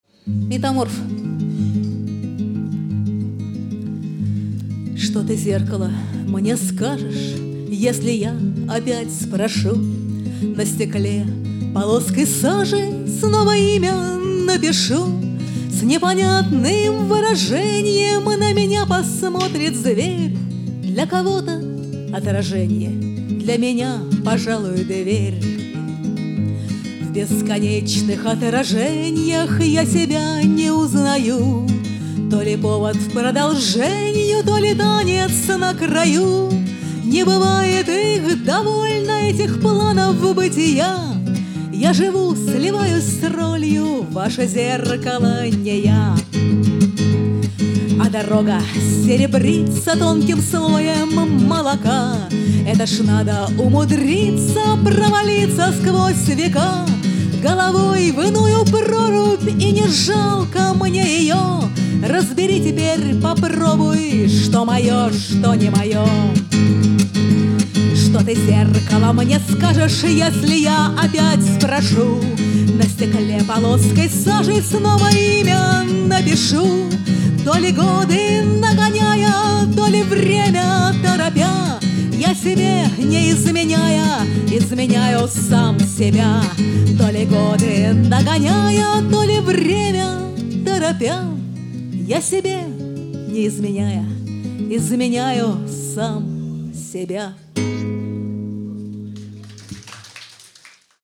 Сольный концерт в «Гарцующем Дредноуте» [Запись с пульта